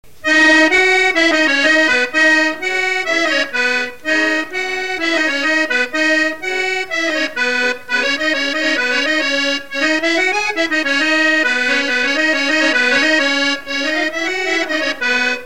polka piquée
Couplets à danser
Pièce musicale inédite